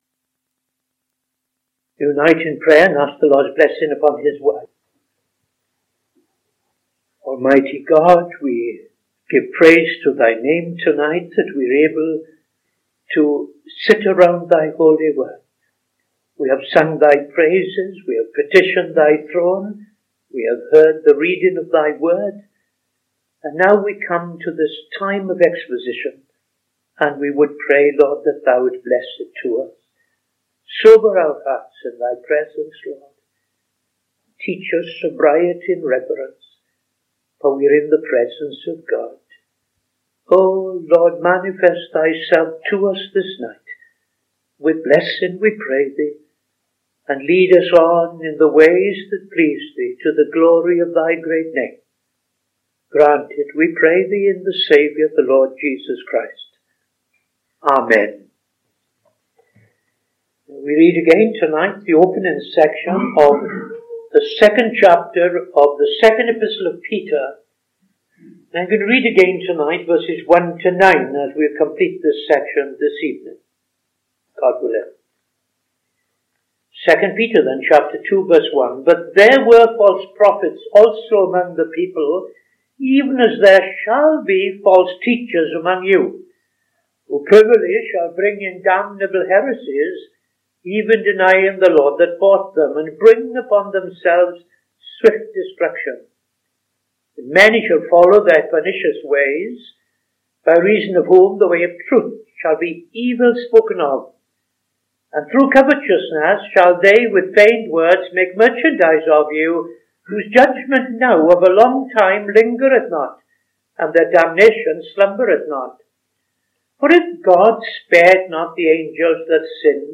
Opening Prayer and Reading II Peter 2:1-9